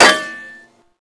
metalsolid3.wav